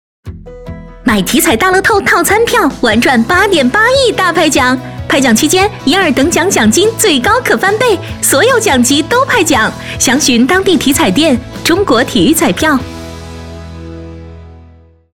2025体彩大乐透8.8亿派奖遇上套餐票15s-女版